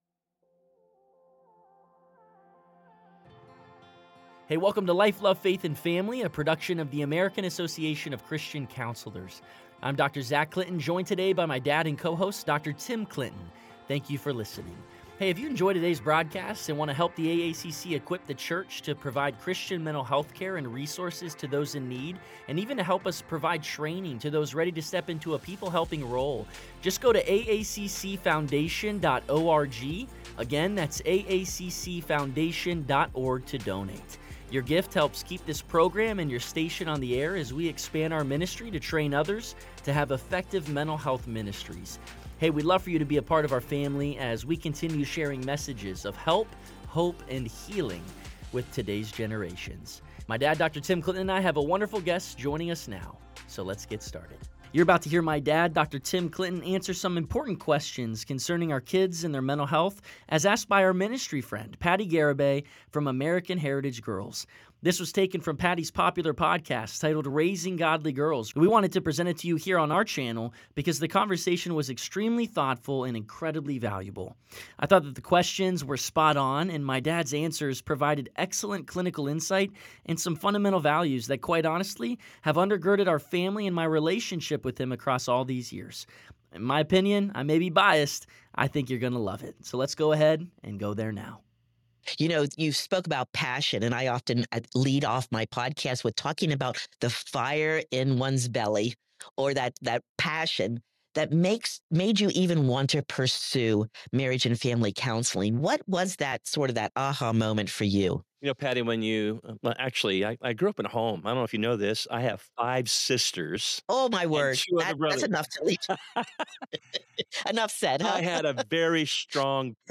A Conversation on the State of Kids’ Mental Health in America